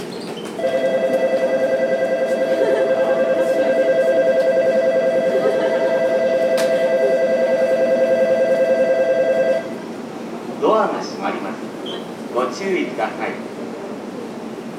倉賀野駅　Kuragano Station ◆スピーカー：ユニペックス小丸型
乗降が少ないため上下線ともベルは長く鳴らしません。
3番線発車ベル